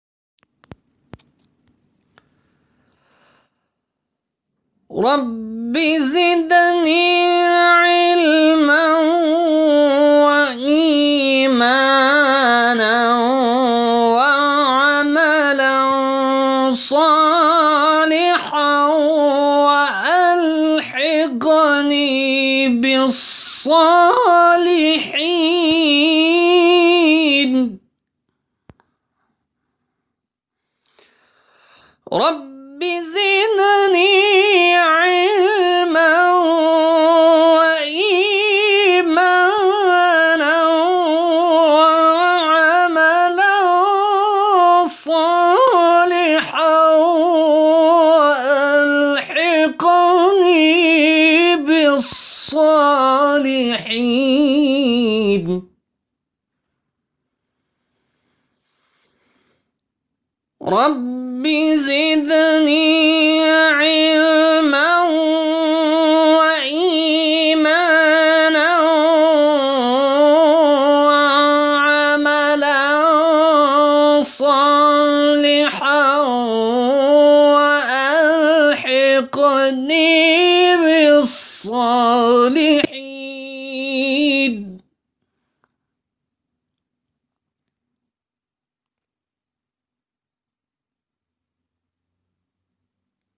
شبکه اجتماعی: فراز‌هایی صوتی از تلاوت قاریان ممتاز کشور ارائه می‌شود.